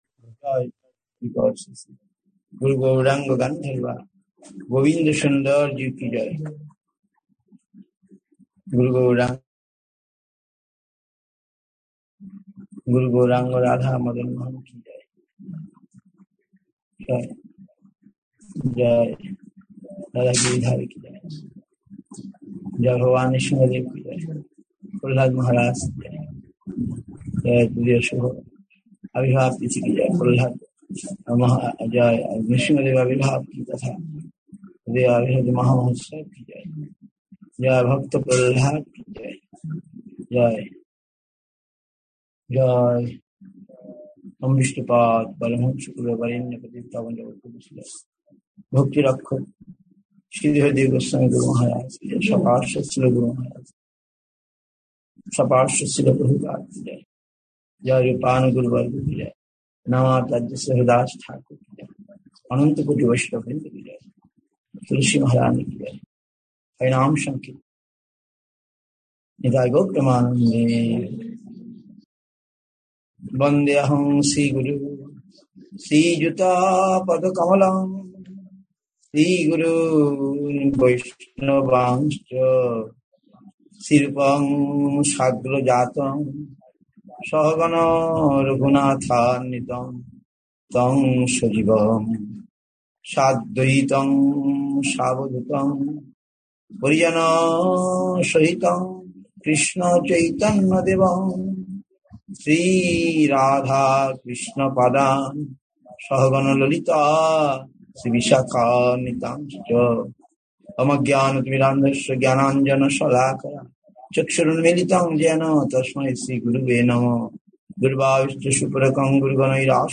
Answers to devotee’s questions;
India, Nabadwip, SREE CAITANYA SRIDHAR SEVA ASHRAM.